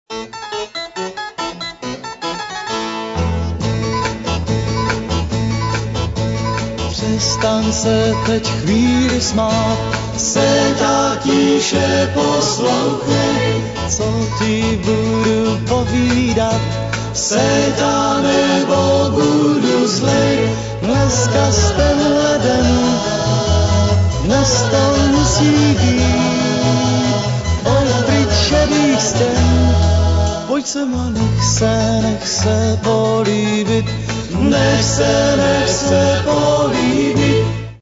kompilace / živé nahr.